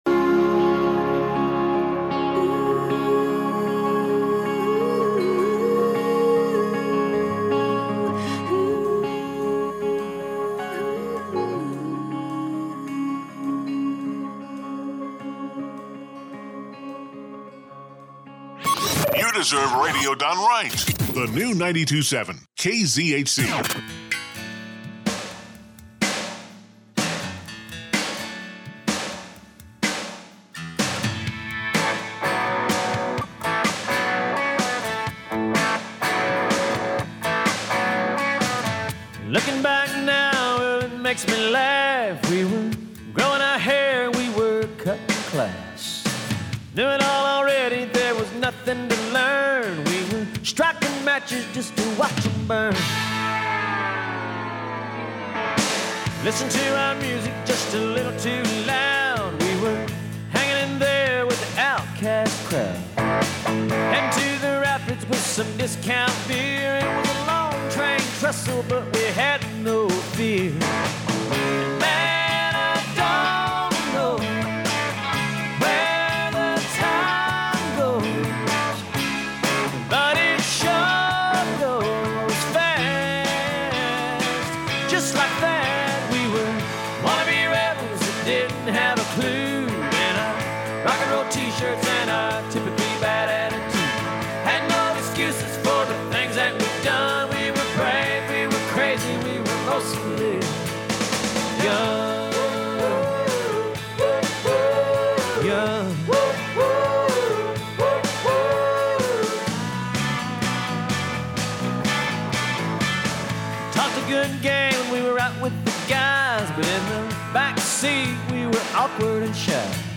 A local Harney County radio interview